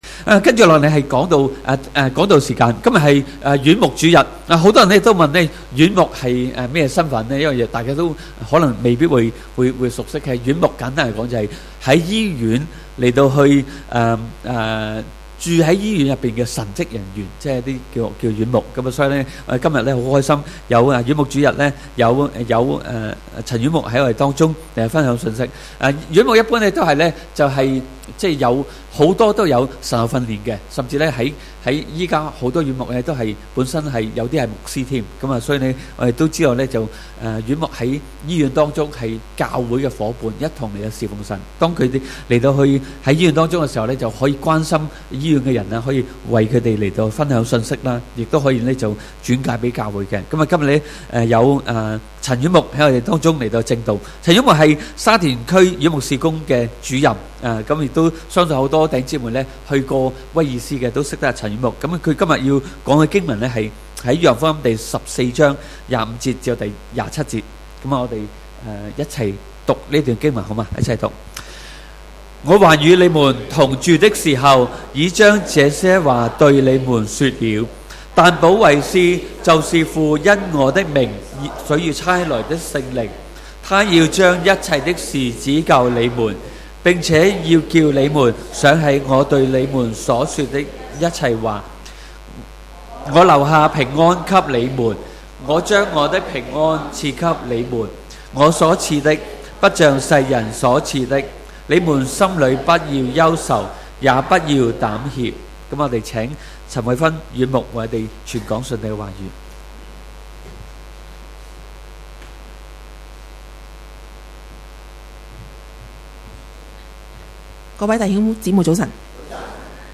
主日崇拜講道 – 主賜平安 (院牧主日)